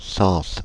Ääntäminen
Ääntäminen Paris: IPA: [sɑ̃s] France (Île-de-France): IPA: /sɑ̃s/ Haettu sana löytyi näillä lähdekielillä: ranska Käännöksiä ei löytynyt valitulle kohdekielelle.